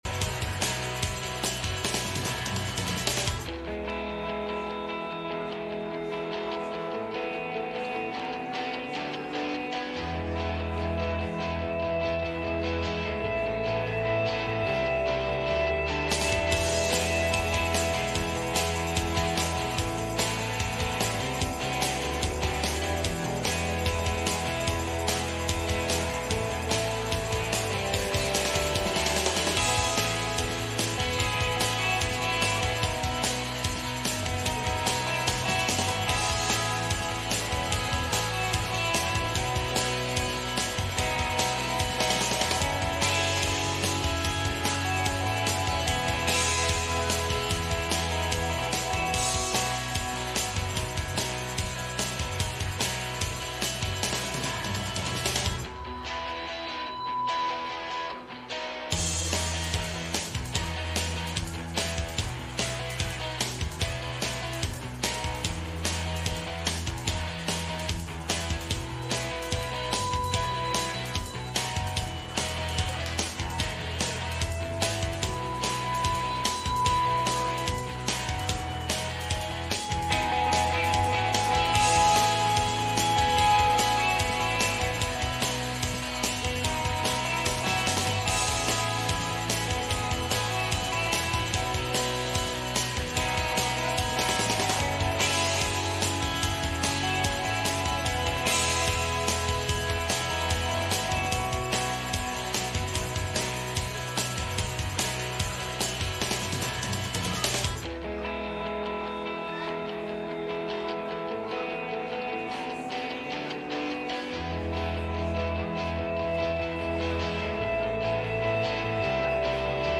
Passage: Genesis 12:1-7 Service Type: Sunday Morning